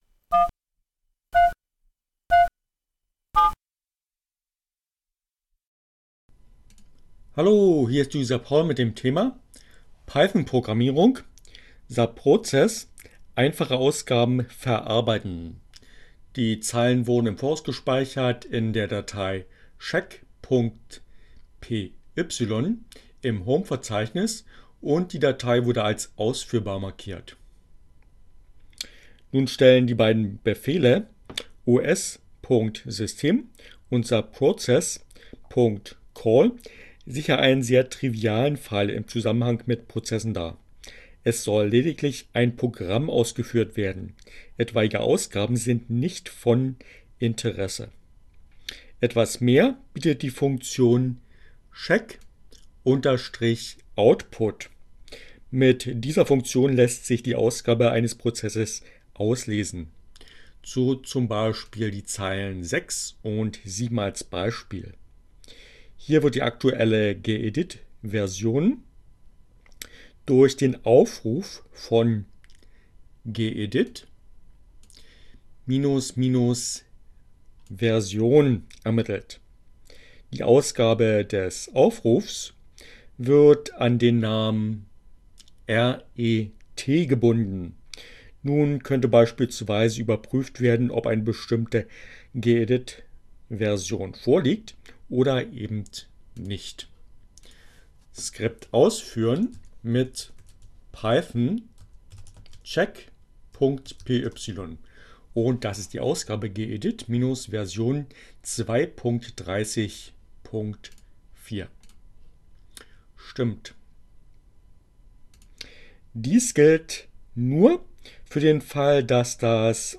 Tags: CC by-sa, Linux, Neueinsteiger, ohne Musik, screencast, Python, Programmierung, subprocess